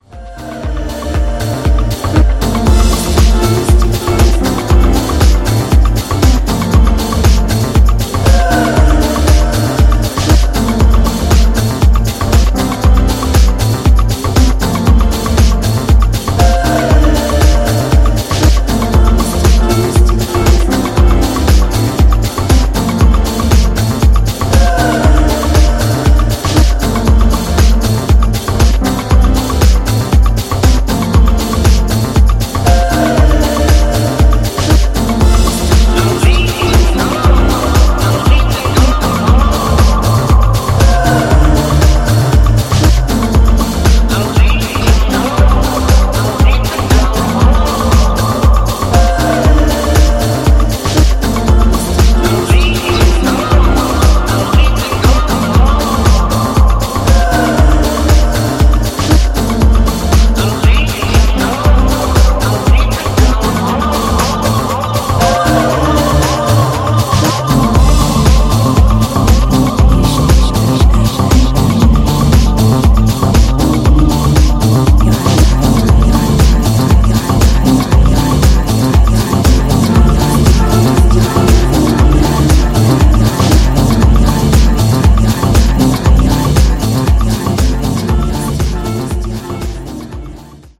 ジャンル(スタイル) DEEP HOUSE / NU DISCO / BALEARIC